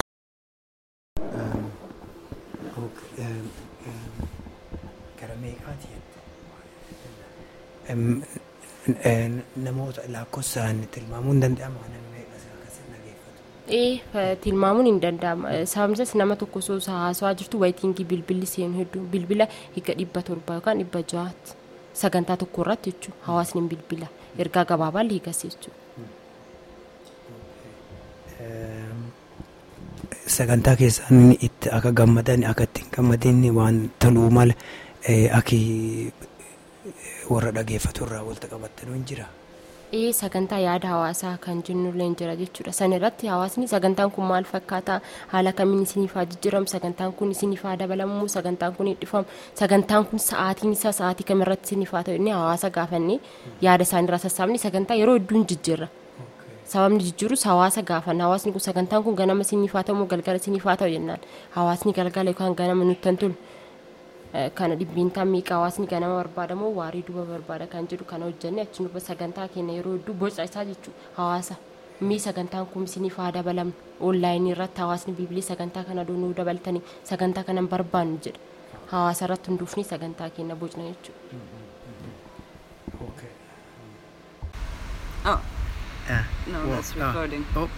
Interview hd progammes